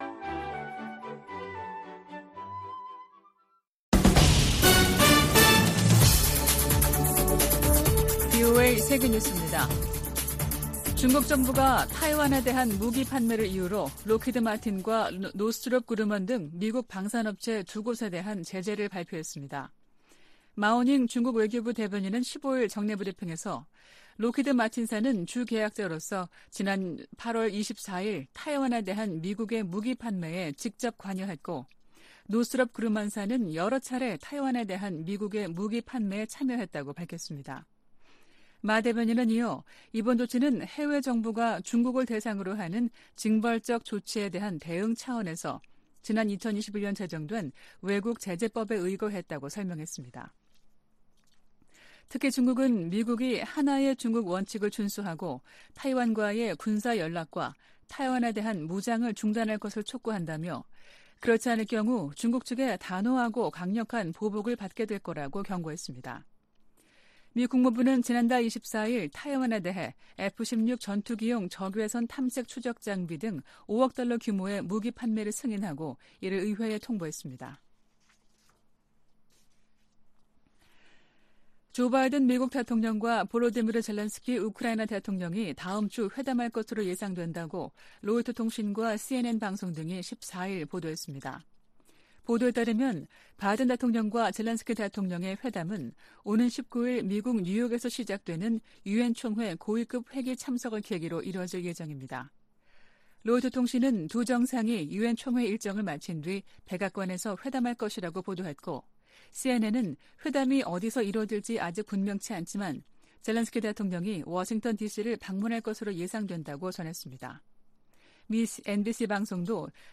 VOA 한국어 아침 뉴스 프로그램 '워싱턴 뉴스 광장' 2023년 9월 16일 방송입니다. 미국과 한국은 유엔 안보리 결의에 부합하지 않는 북한과 러시아 간 협력에 강력한 우려를 표명한다고 밝혔습니다. 미 국방부는 북한과 러시아 간 추가 무기 거래 가능성과 관련, 민간인 학살에 사용되는 무기를 제공해선 안 된다고 거듭 강조했습니다. 미국 정부가 한국에 대한 50억 달러 상당 F-35 스텔스 전투기 25대 판매를 승인했습니다.